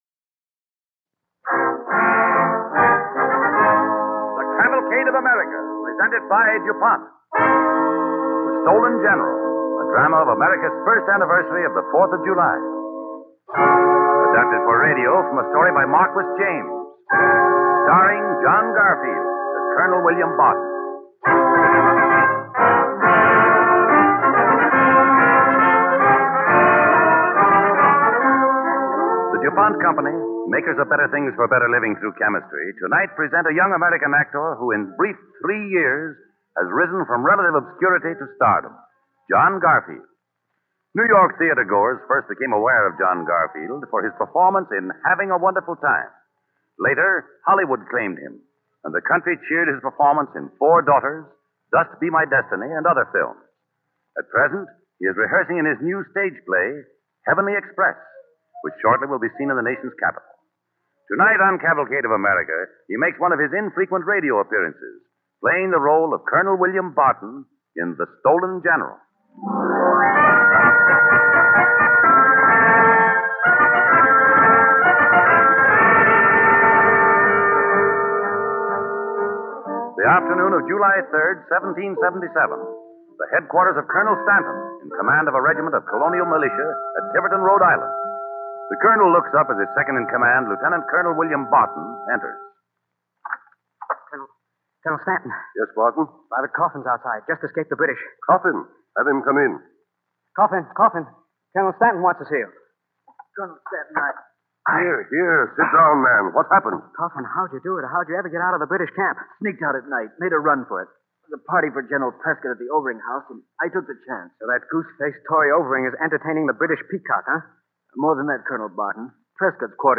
Cavalcade of America Radio Program
The Stolen General, starring John Garfield